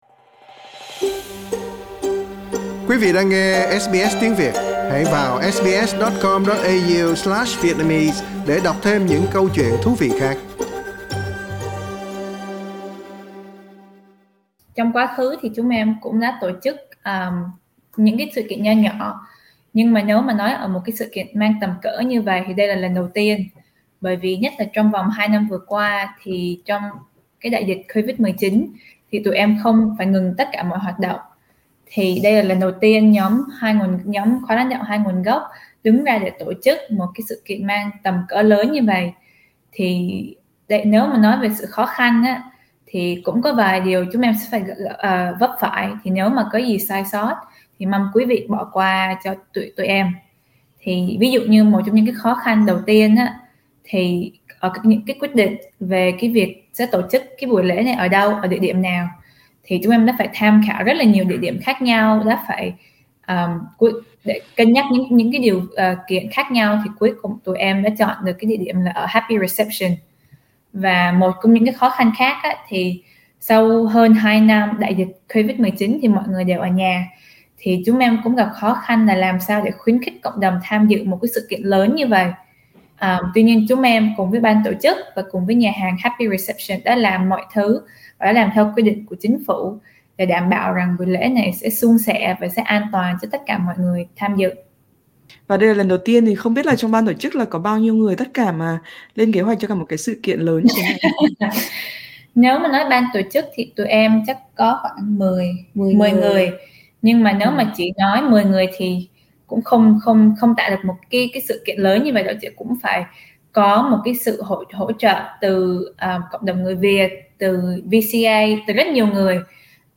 SBS trò chuyện với hai thành viên trong nhóm tổ chức